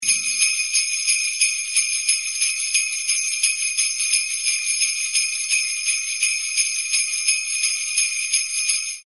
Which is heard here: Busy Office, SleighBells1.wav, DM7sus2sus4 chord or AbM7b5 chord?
SleighBells1.wav